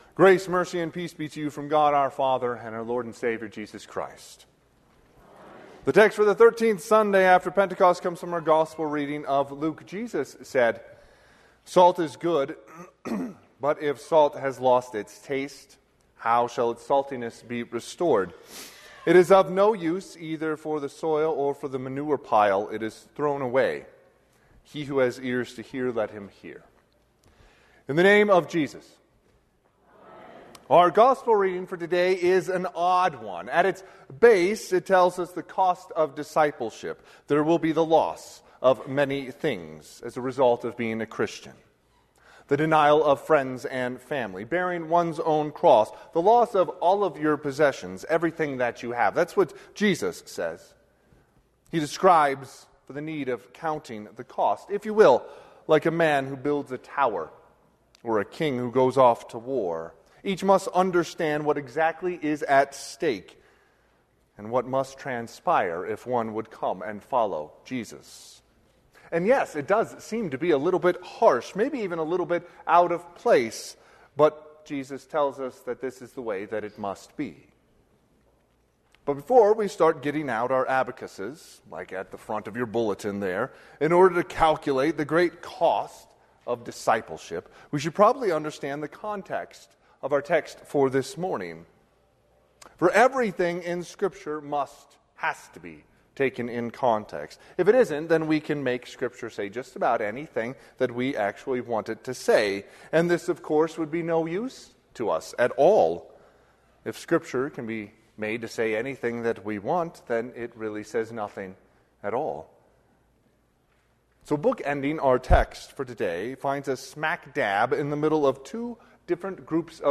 Sermon - 9/7/2025 - Wheat Ridge Evangelical Lutheran Church, Wheat Ridge, Colorado
Thirteenth Sunday after Pentecost